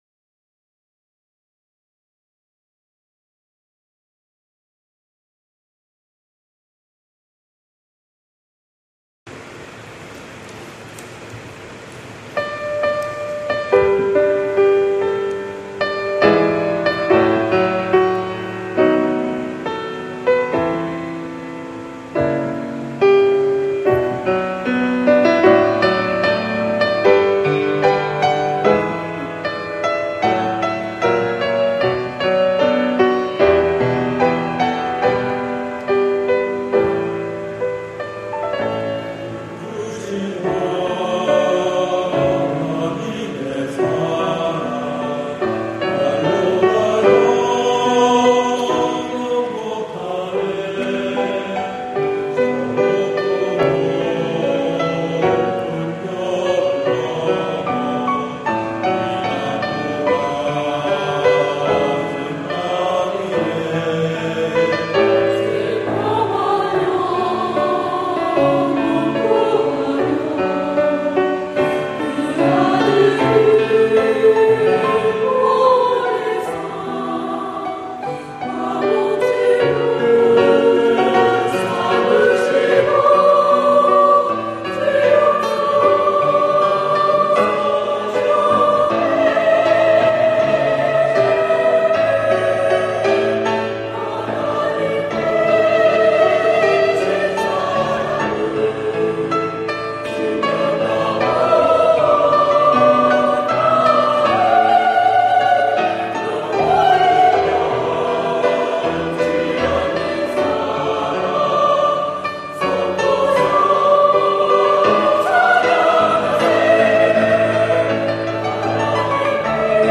그 크신 하나님의 사랑 > 찬양영상